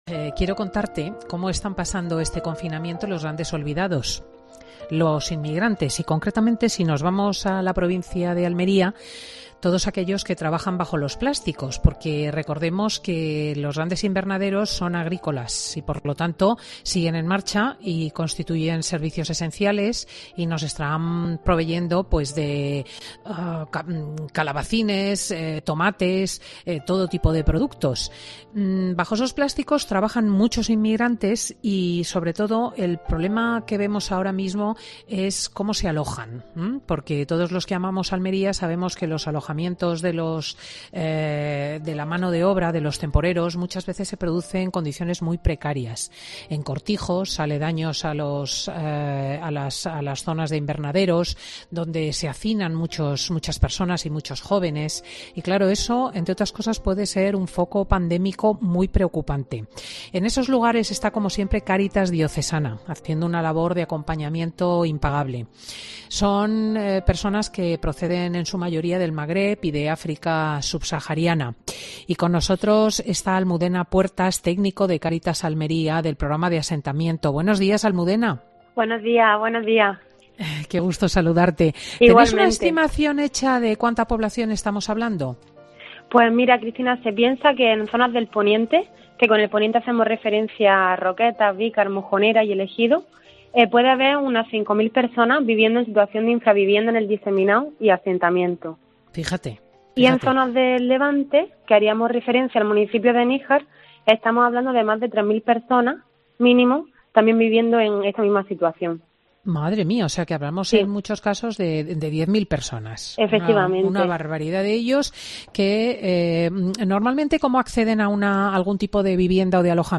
Escucha toda la entrevista con Cristina López Schlichting en Fin de Semana